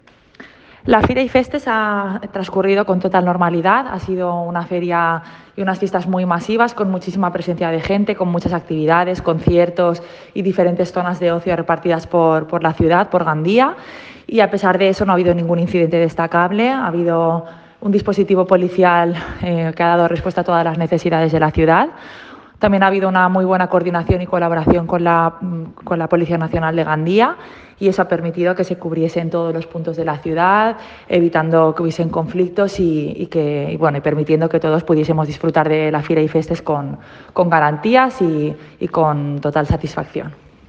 Escucha aquí a la edila de Seguridad Ciudadana,